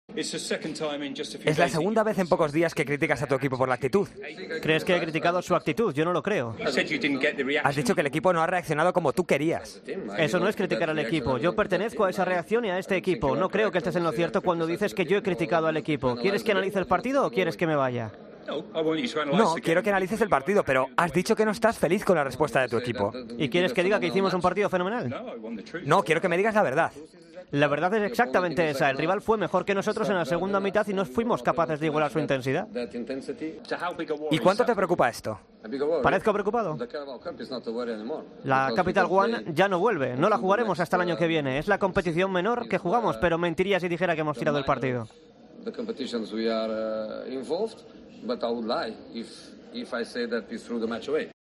AUDIO: Mourinho se peleó en rueda de prensa con un periodista después de perder en la Carabao Cup con un segunda división.